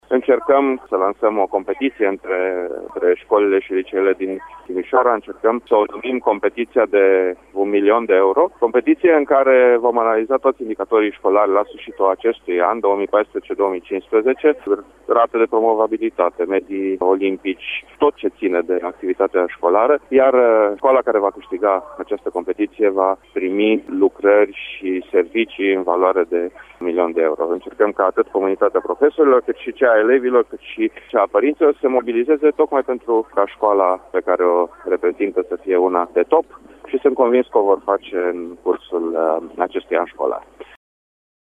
Videprimarul Dan Diaconu, a anunțat la Radio Timișoara, că banii vor reveni școlilor care înregistrează rezultate deosebite.